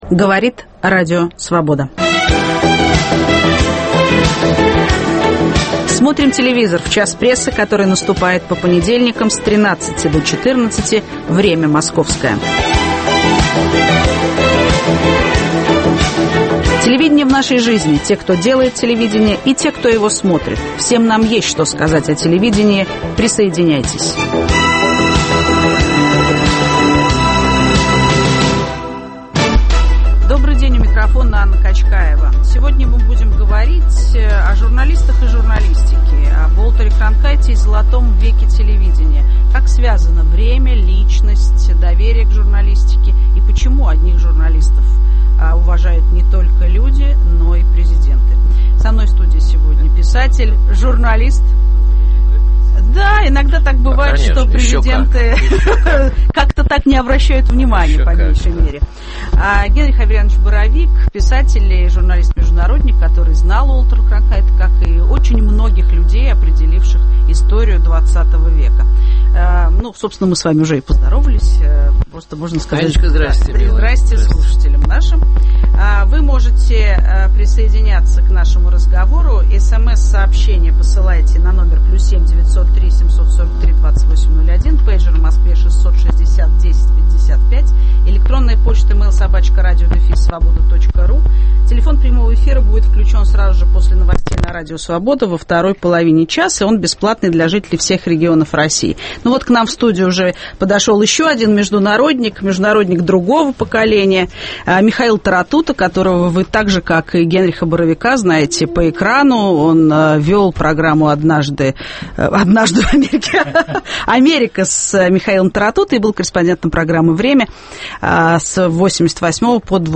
Уолтер Кронкайт и "золотой век" телевидения. О времени и журналистах, которым доверяют люди и уважают президенты говорим с журналистами-международниками Генрихом Боровиком и Михаилом Таратутой.